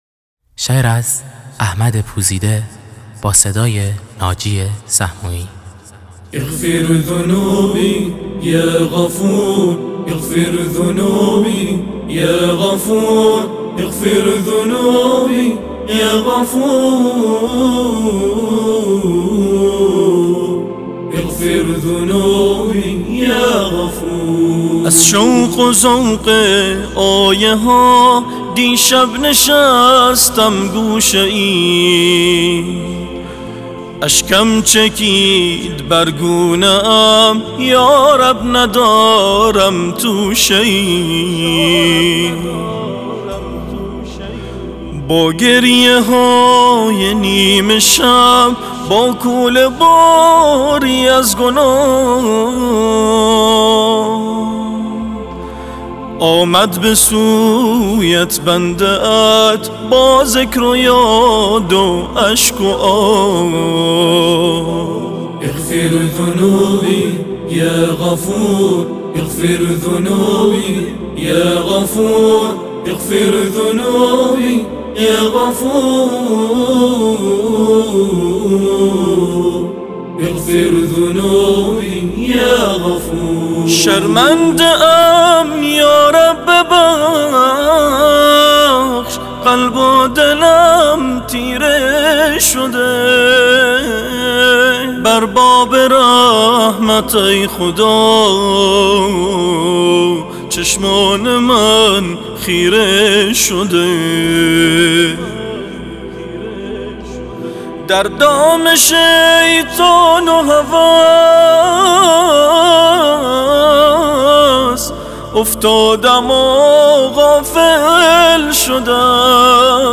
نشید نیاز